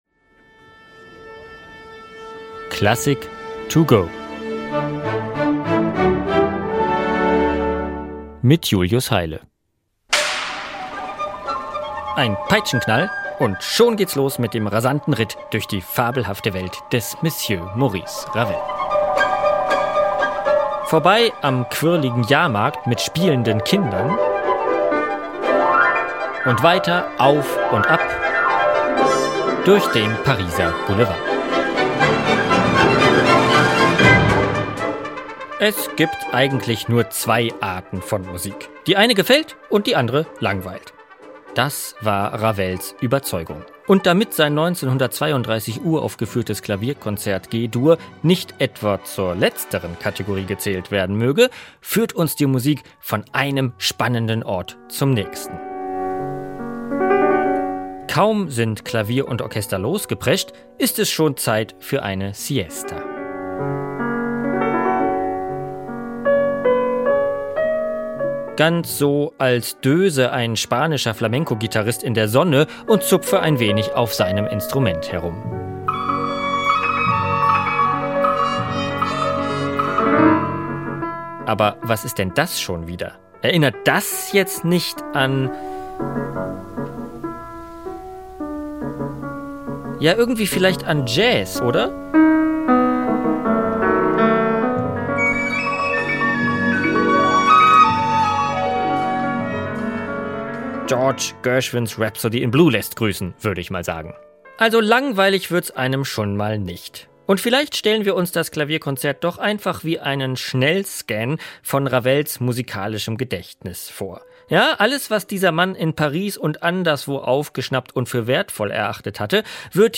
über das Werk in der Kurzeinführung für unterwegs.